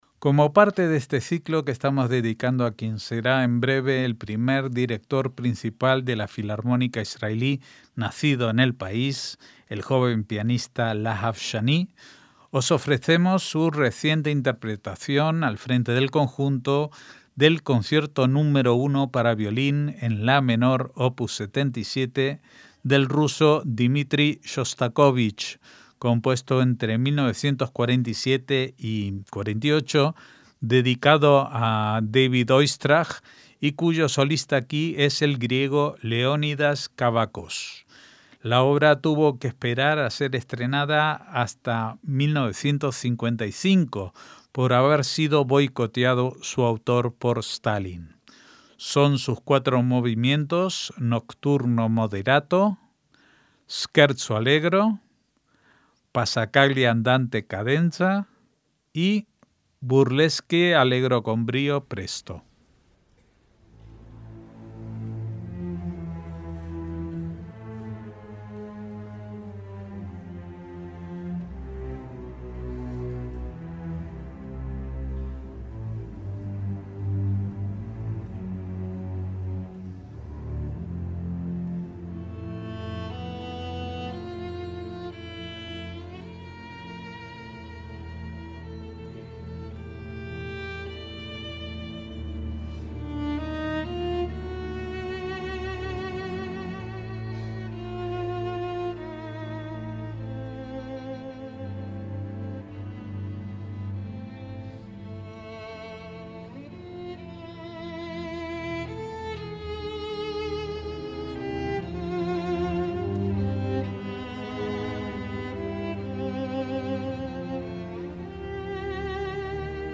MÚSICA CLÁSICA
para violín en la menor